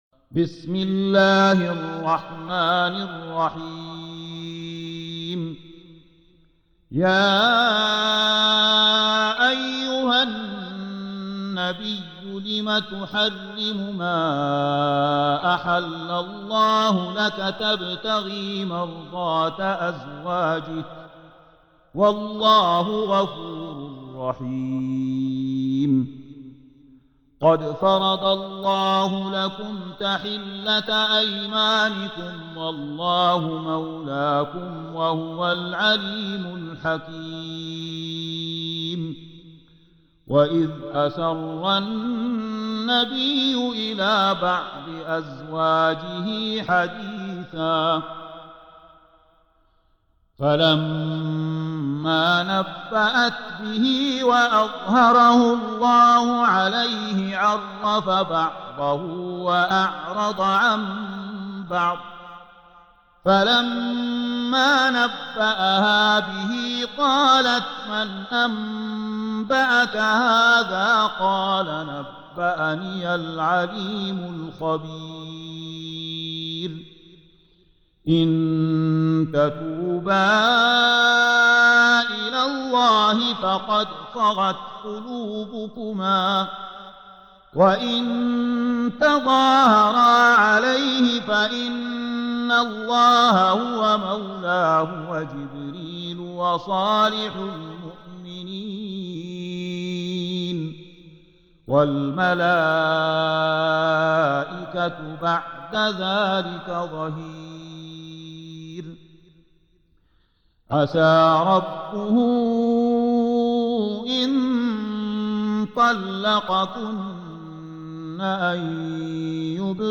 Surah Sequence تتابع السورة Download Surah حمّل السورة Reciting Murattalah Audio for 66. Surah At-Tahr�m سورة التحريم N.B *Surah Includes Al-Basmalah Reciters Sequents تتابع التلاوات Reciters Repeats تكرار التلاوات